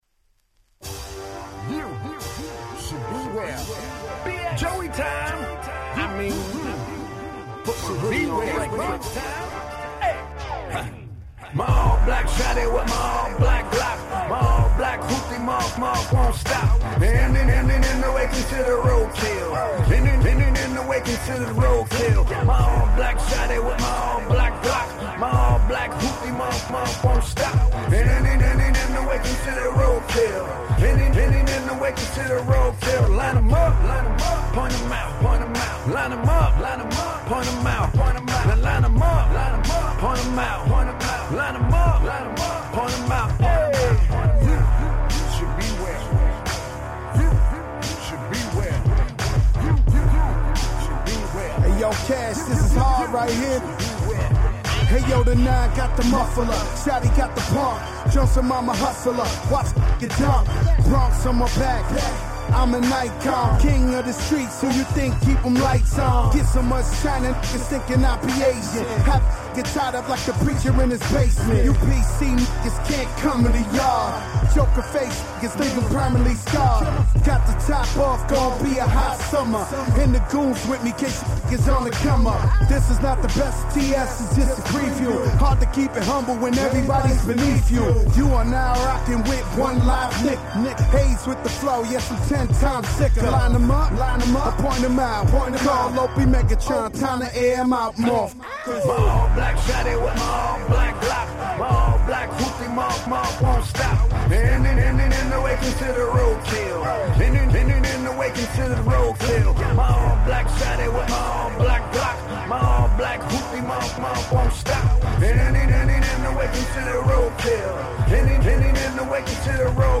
10' Nice Hip Hop !!